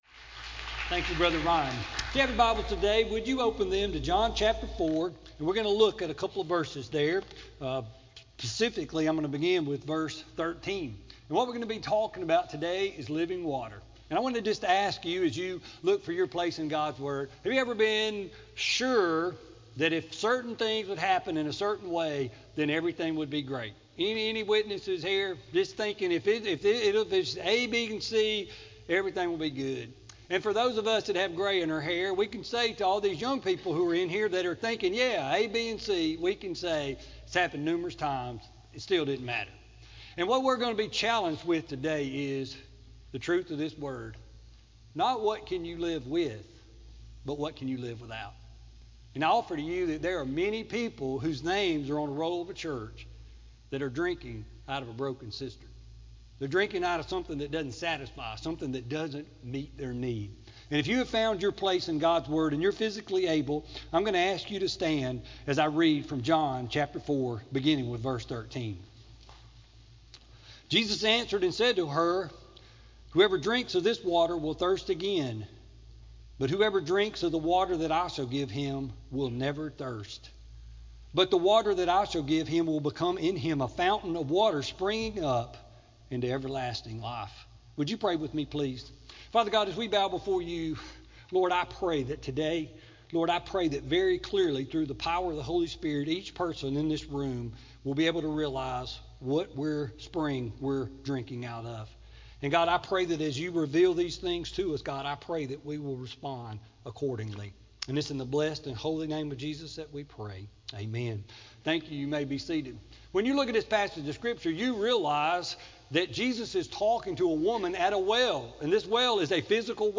Sermon-1-3-16-CD.mp3